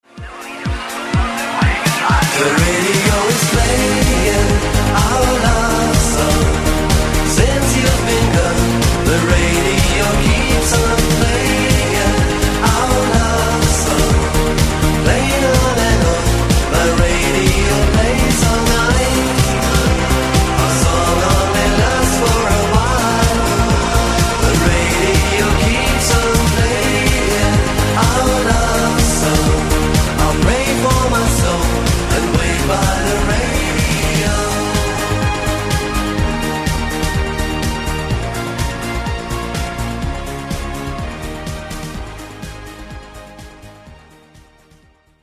поп
мужской вокал
Italo Disco
Synth Pop
Dance Pop
романтичные
europop